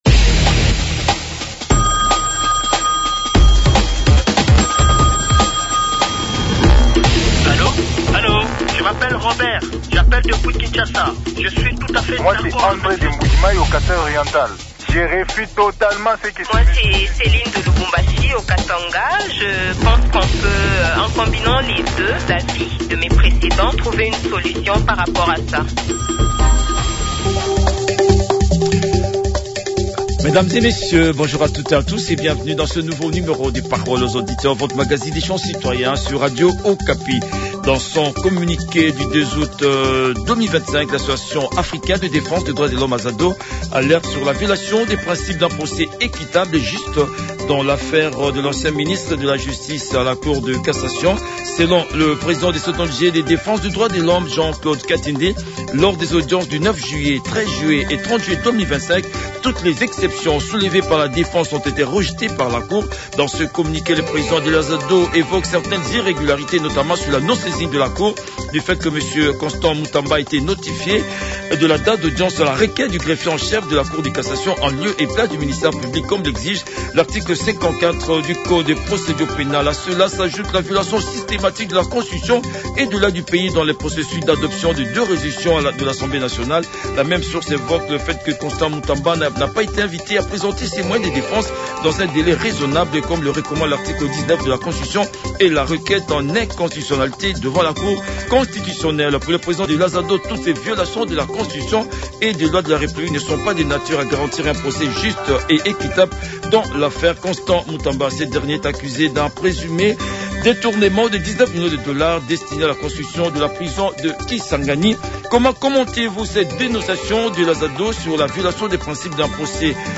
Le débat citoyen